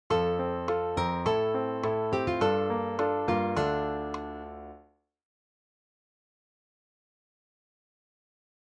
F  Am  Gm9  Dm [